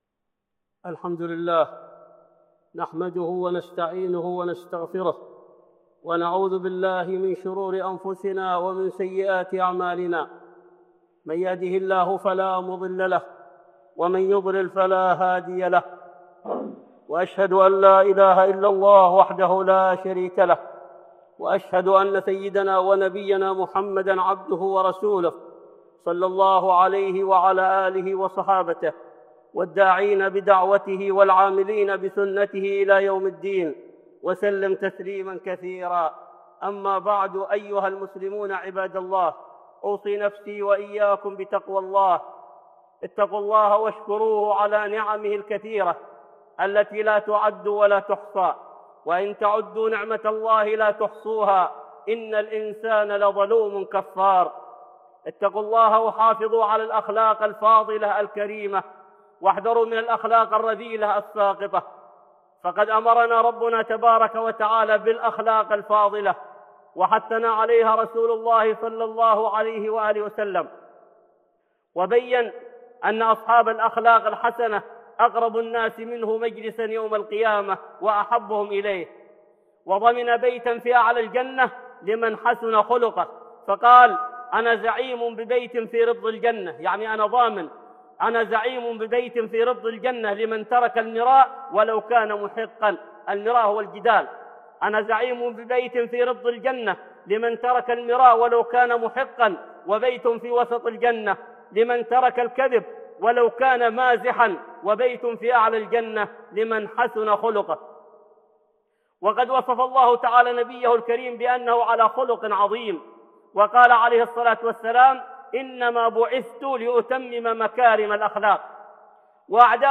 (خطبة جمعة) الغيرة والحياء 1